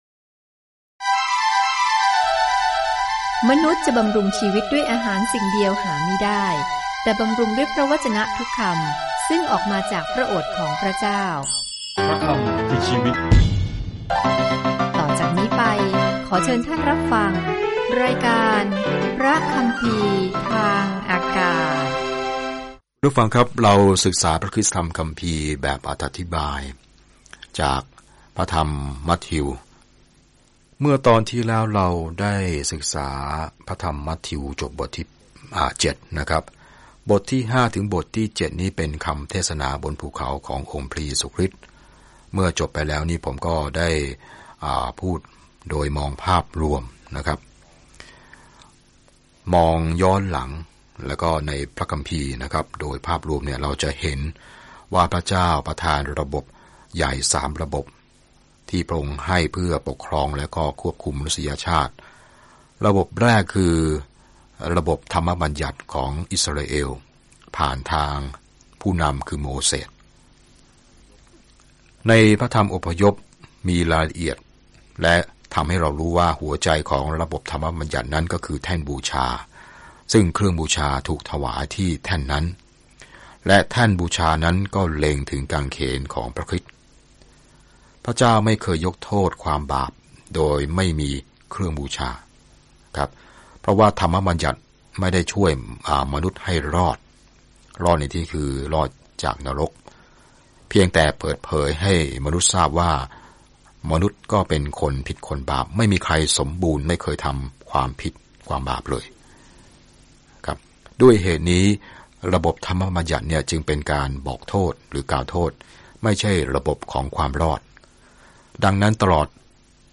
มัทธิวพิสูจน์ให้ผู้อ่านชาวยิวเห็นข่าวดีว่าพระเยซูคือพระเมสสิยาห์ของพวกเขาโดยแสดงให้เห็นว่าพระชนม์ชีพและพันธกิจของพระองค์ทำให้คำพยากรณ์ในพันธสัญญาเดิมเกิดสัมฤทธิผลอย่างไร เดินทางทุกวันผ่านมัทธิวในขณะที่คุณฟังการศึกษาด้วยเสียงและอ่านข้อที่เลือกจากพระวจนะของพระเจ้า